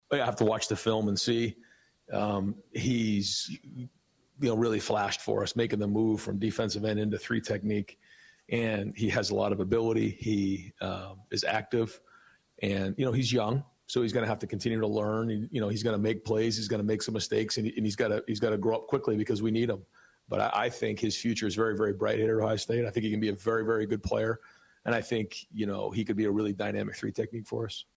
EXCERPTS FROM RYAN DAY’S POSTGAME PRESS CONFERENCE